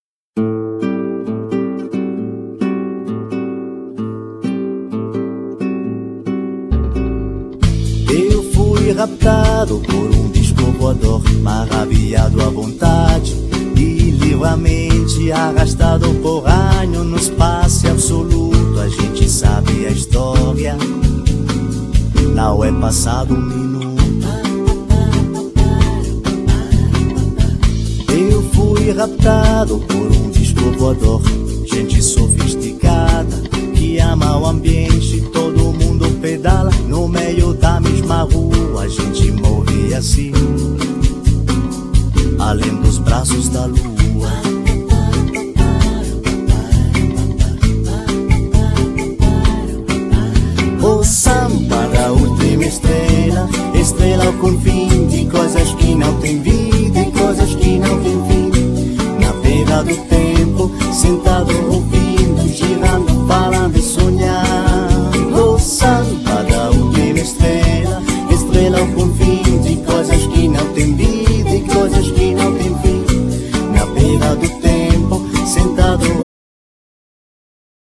Voce
Chitarra Elettrica
Chitarra Acustica
Pianoforte, Tastiere
Batteria
Basso
Genere : Pop